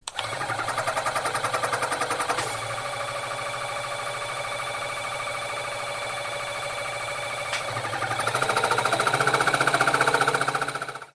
Шум зубного ирригатора